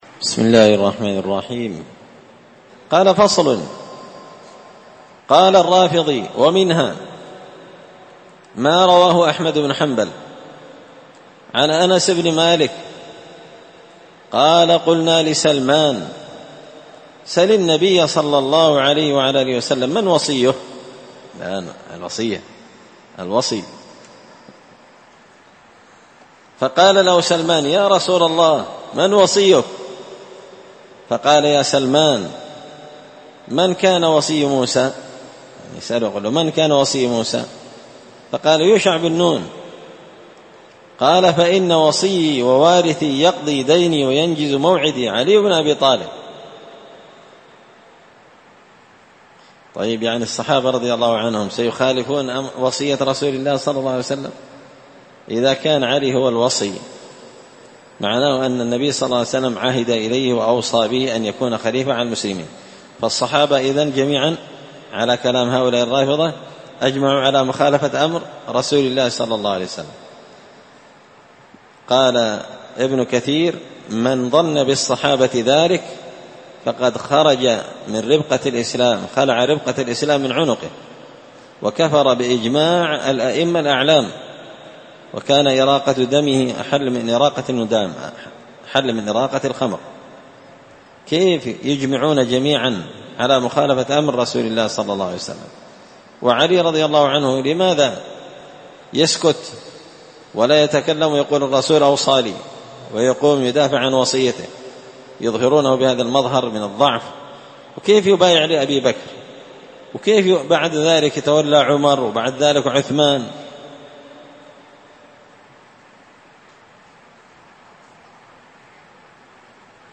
الأربعاء 11 ذو القعدة 1444 هــــ | الدروس، دروس الردود، مختصر منهاج السنة النبوية لشيخ الإسلام ابن تيمية | شارك بتعليقك | 7 المشاهدات
مسجد الفرقان قشن_المهرة_اليمن